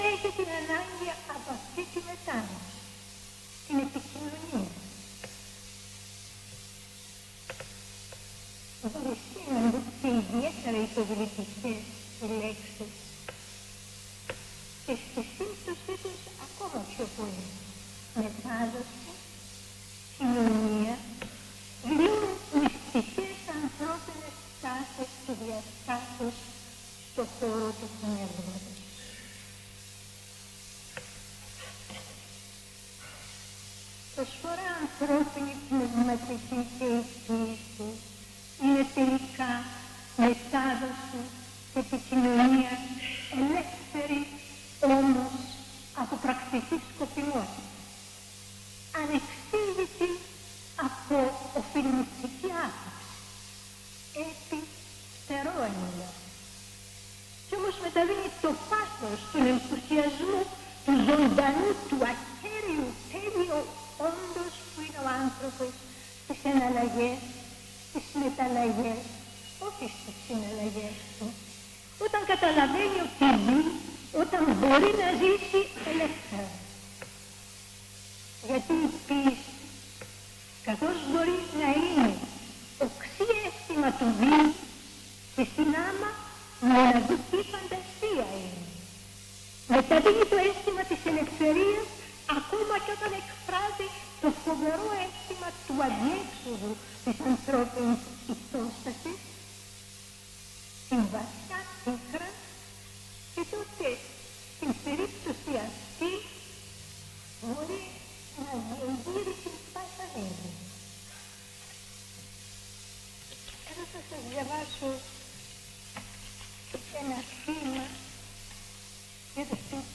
Εξειδίκευση τύπου : Εκδήλωση
Περιγραφή: Εισαγωγική Ομιλία του Γ. Π. Σαββίδη
Περίληψη: Η Ζωή Καρέλλη διαβάζει ποιήματά της